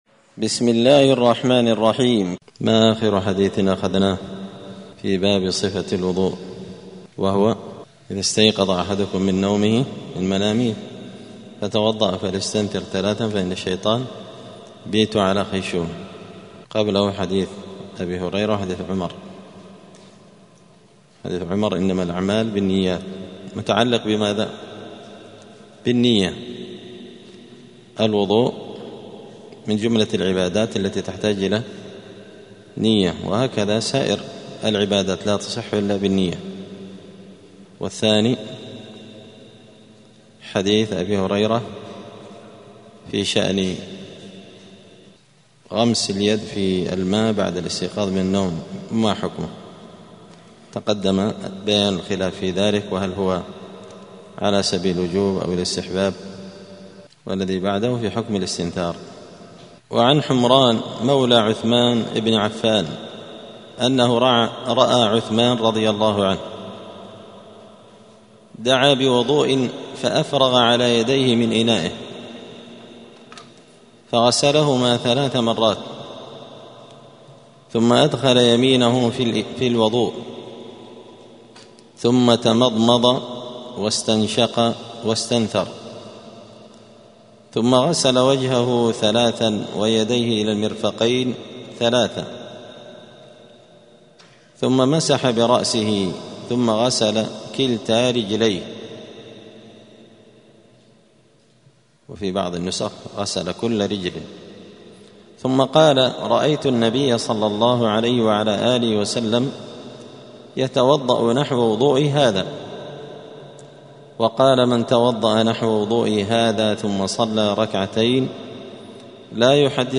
دار الحديث السلفية بمسجد الفرقان بقشن المهرة اليمن
*الدرس السادس والعشرون [26] {باب صفة الوضوء حكم التسمية عند الوضوء…}*